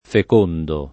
fecondo [ fek 1 ndo ]